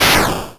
ihob/Assets/Extensions/explosionsoundslite/sounds/bakuhatu145.wav at master
bakuhatu145.wav